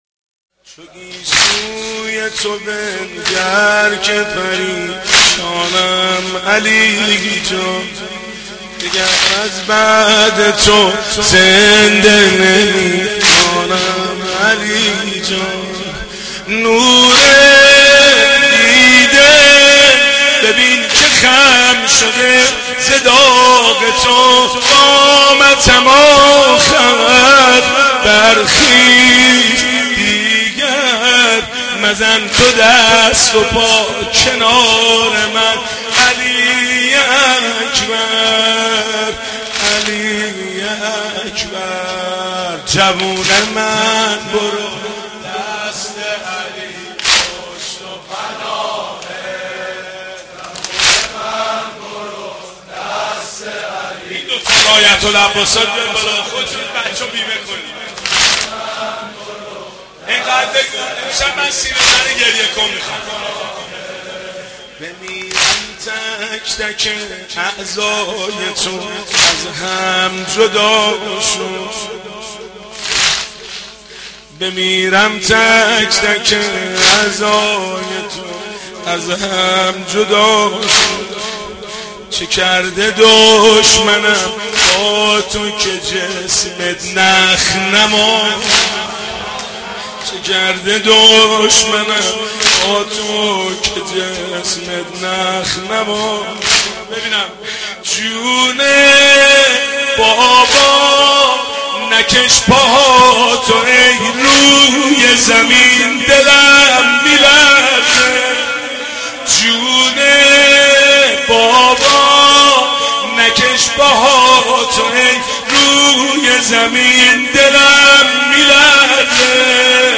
محرم 88 - سینه زنی 3
محرم-88---سینه-زنی-3